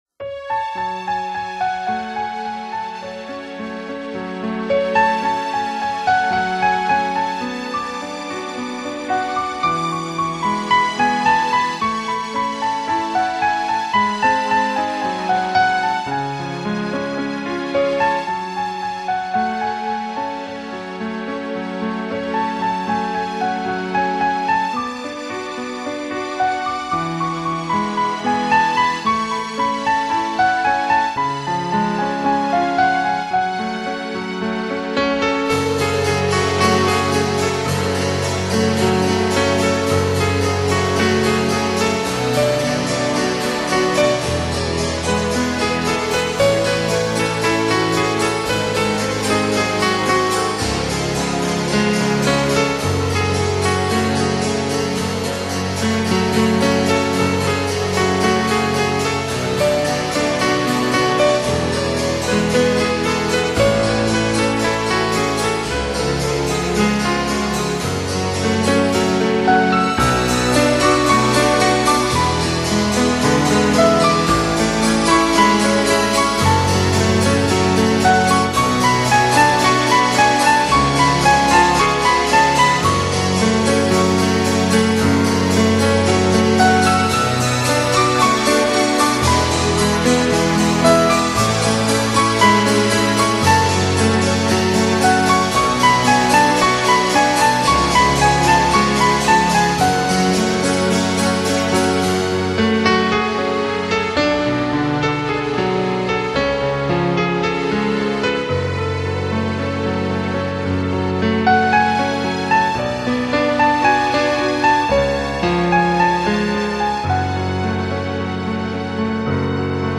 Genre: New Age,Instrumental